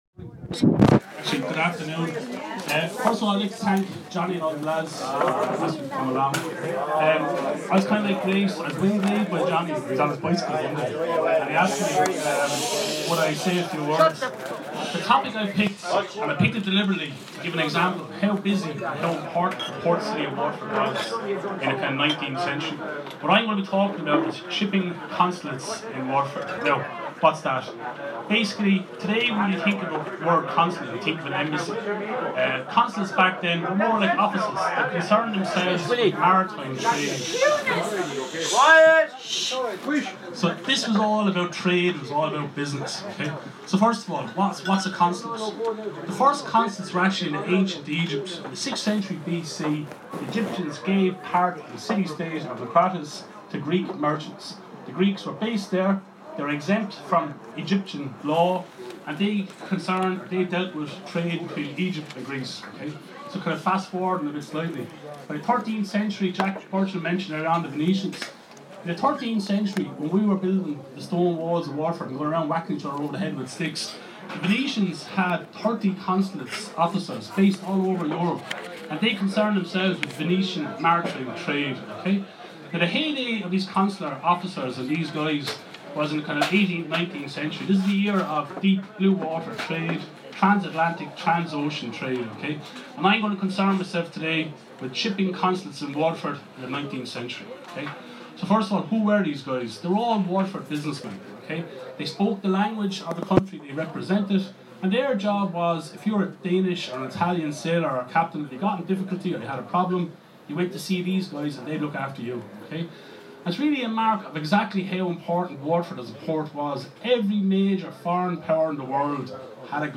Blues blaas and banter at Imagine Festival